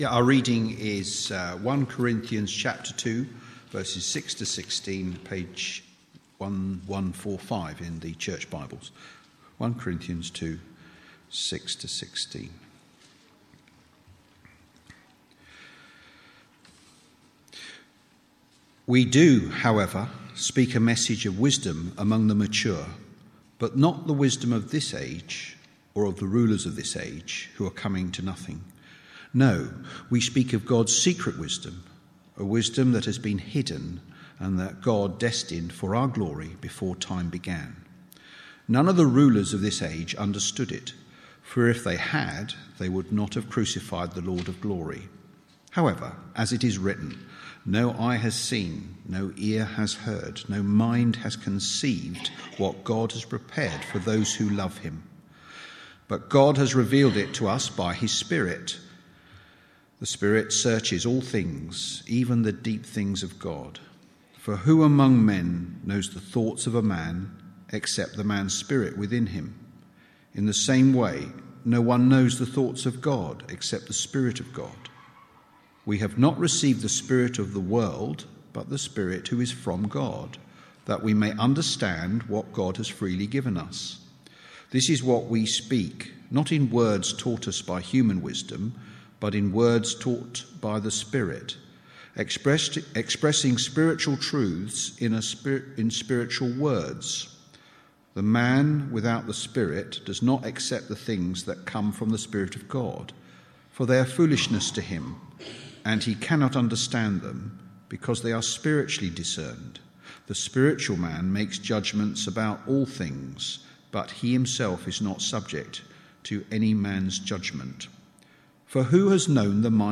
1 Corinthians Passage: 1 Corinthians 2:6-16 Service Type: Sunday Morning « Power and Wisdom Kingdom Growth